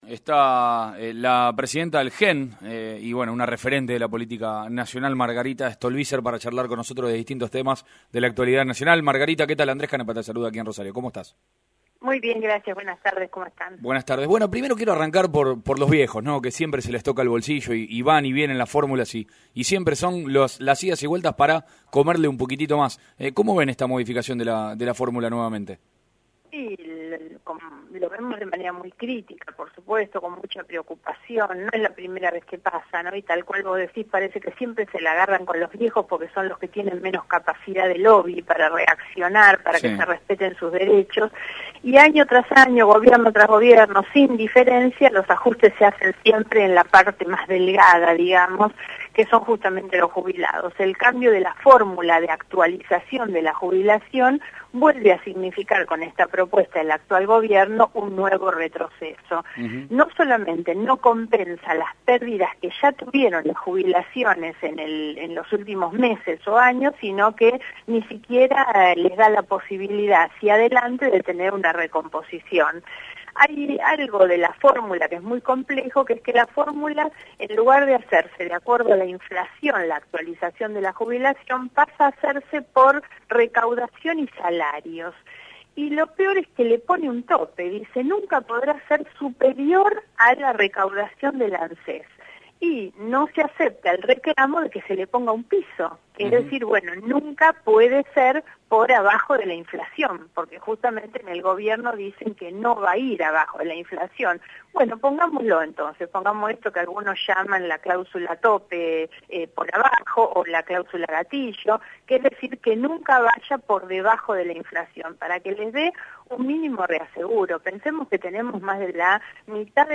Margarita Stolbizer en Radio Mitre Rosario